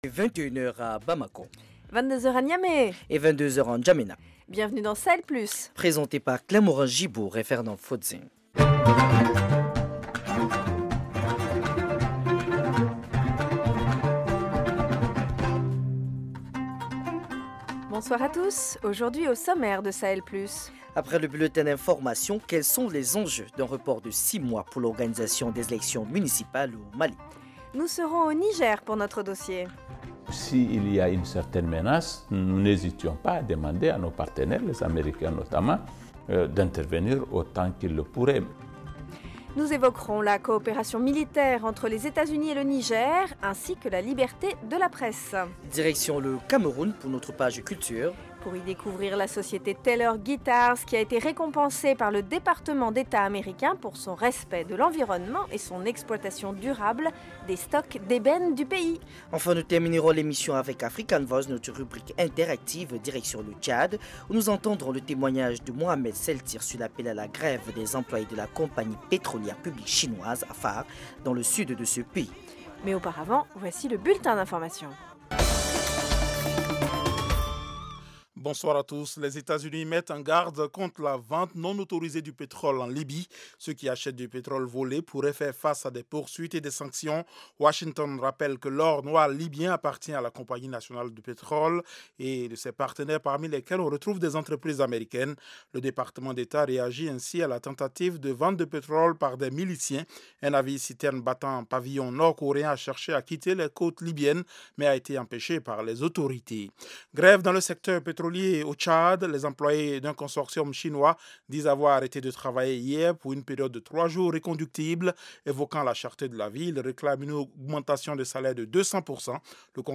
Dossier : grand entretien avec Mohammed Bazoum, le ministre des Affaires Etrangères du Niger. Il revient sur l’engagement américain dans la lutte terroriste dans le Sahel.